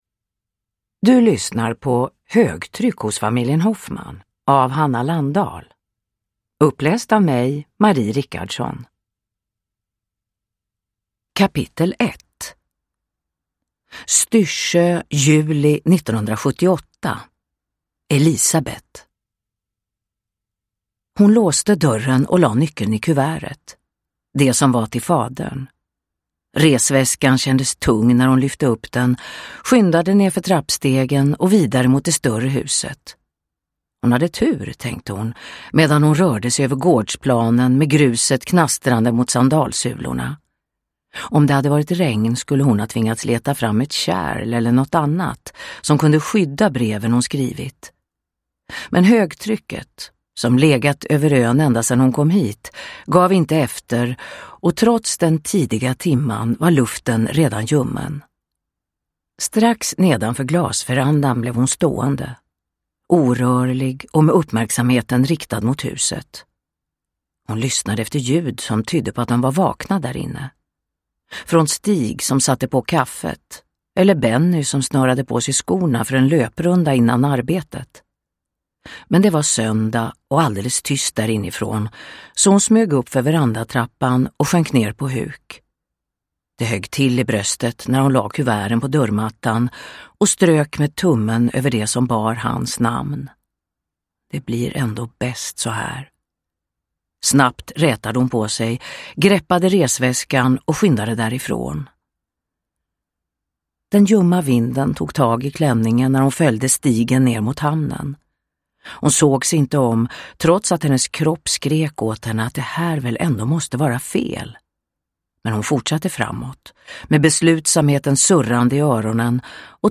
Högtryck hos familjen Hoffman (ljudbok) av Hanna Landahl